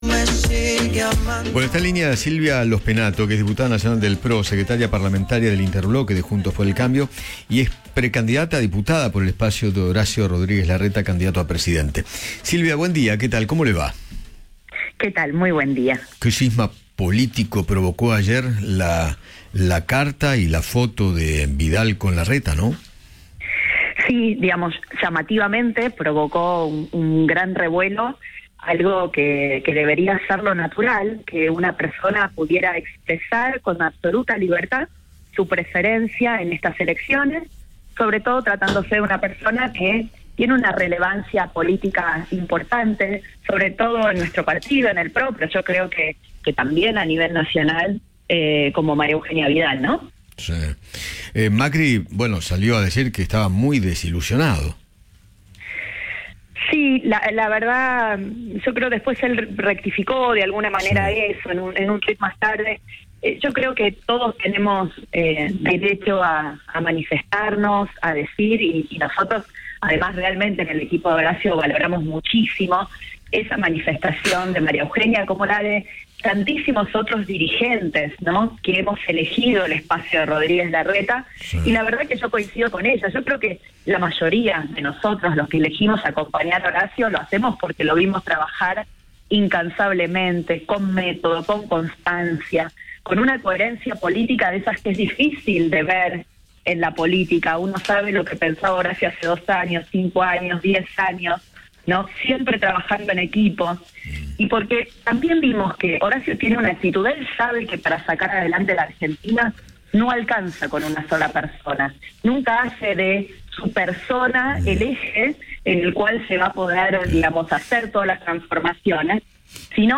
Silvia Lospennato, diputada Nacional y precandidata a diputada por el espacio de Rodriguez Larreta, habló con Eduardo Feinmann sobre la muestra de apoyo de Vidal a Horacio y analizó las PASO que se vienen.